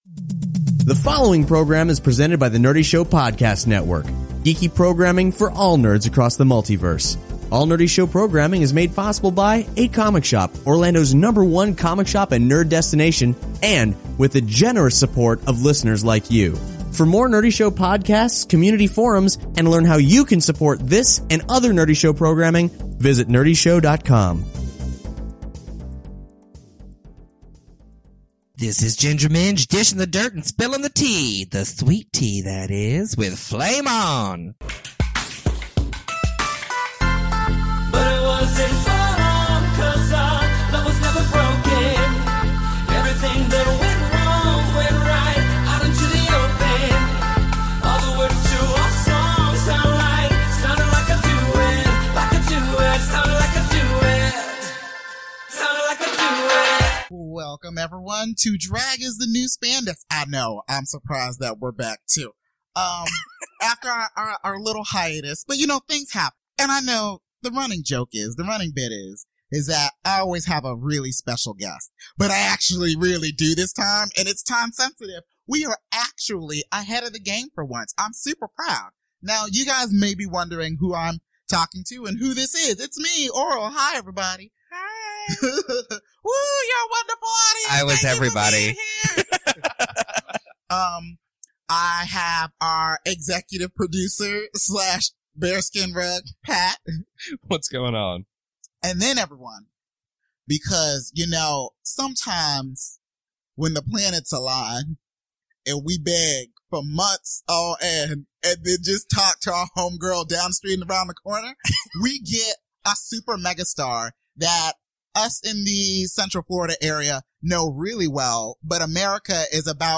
So enjoy our interview with Ms. Minj and get ready to start your engines for RuPaul’s Drag Race Season 7!